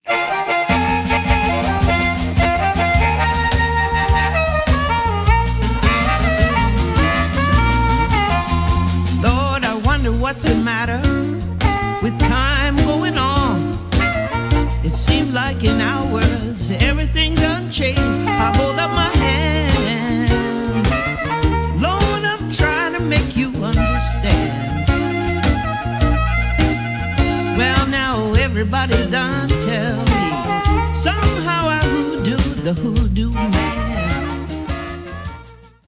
CONCERT HIGHLIGHTS!!